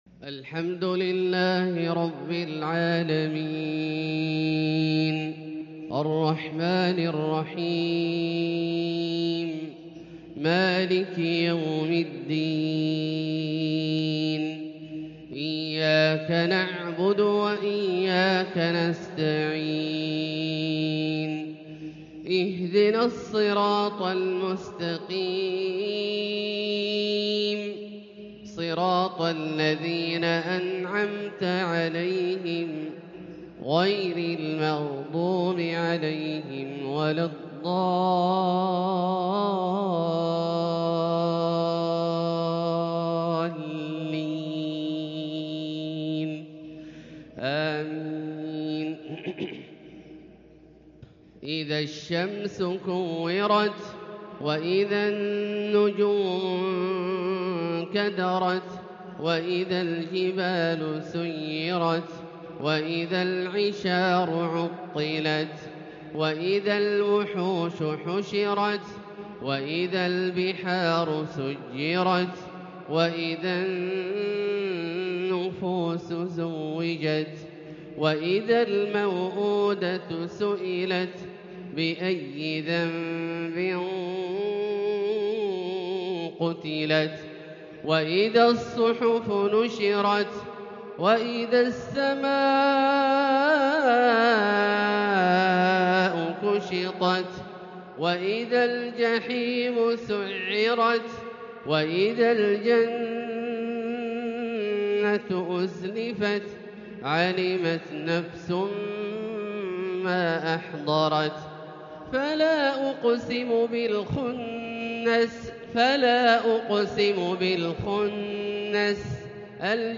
فجر السبت 7-2-1444هـ سورتي التكوير و الانفطار | Fajr prayer from Surah AtTakwir and Al-Infitar 3-9-2022 > 1444 🕋 > الفروض - تلاوات الحرمين